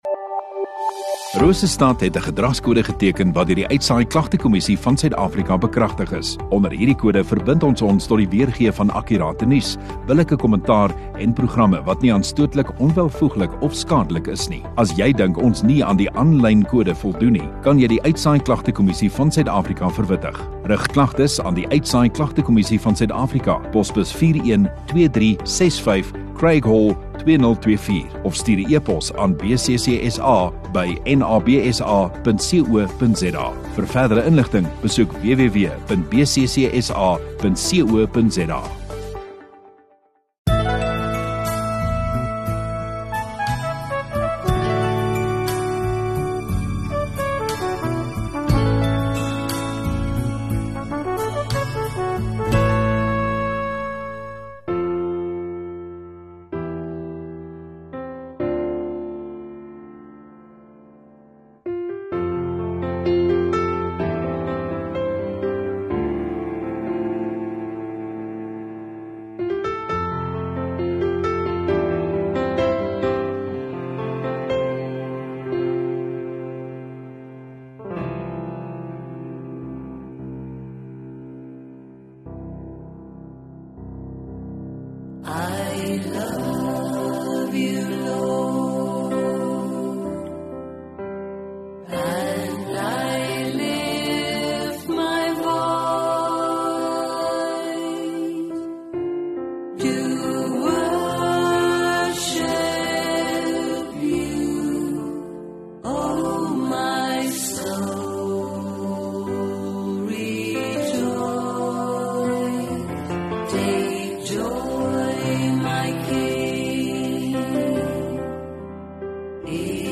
11 May Sondagaand Erediens